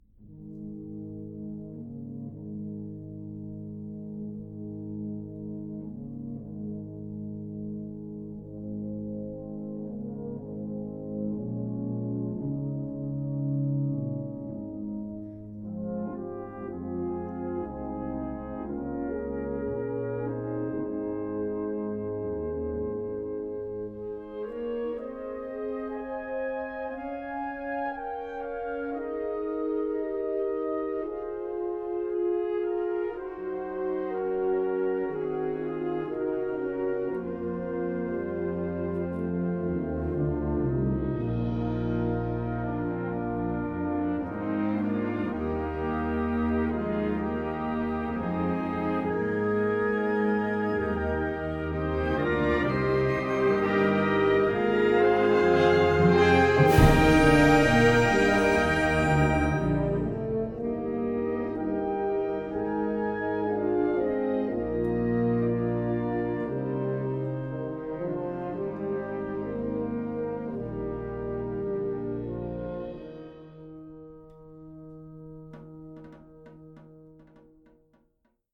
Categorie Harmonie/Fanfare/Brass-orkest
Subcategorie Concertmuziek
Bezetting Ha (harmonieorkest)
Het stuk begint met het rustige hoofdthema.
Dit deel moet dan ook dramatisch en hectisch zijn.